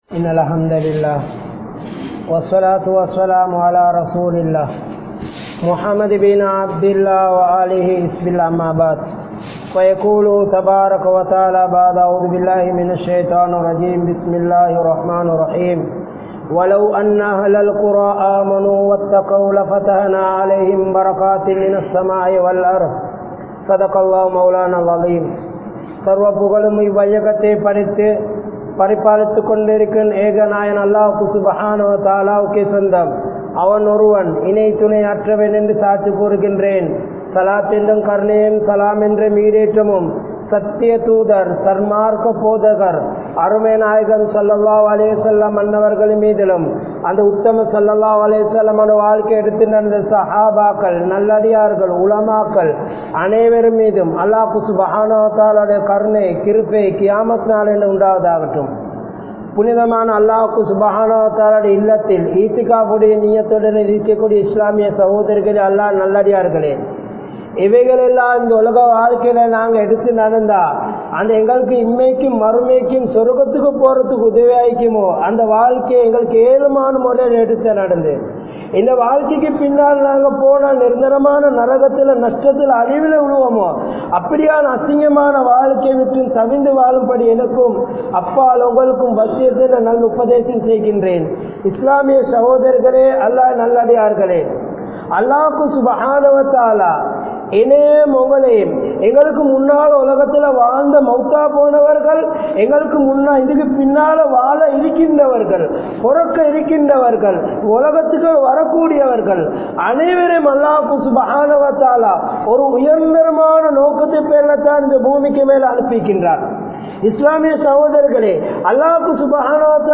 Vaalkaiel Barakkath Veanduma? (வாழ்க்கையில் பரக்கத் வேண்டுமா?) | Audio Bayans | All Ceylon Muslim Youth Community | Addalaichenai